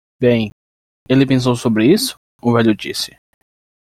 Pronunciado como (IPA) /ˈso.bɾi/